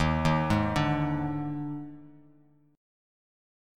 D#M7sus4 chord